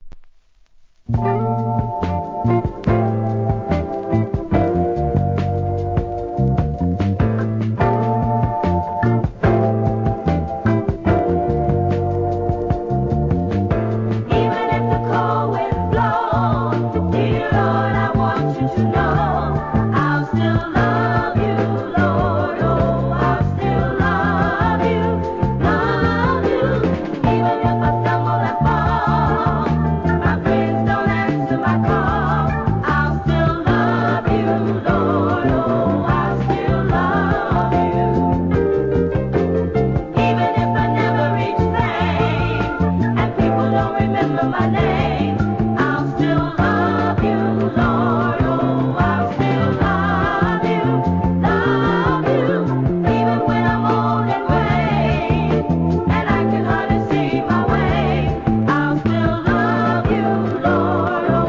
¥ 3,300 税込 関連カテゴリ SOUL/FUNK/etc...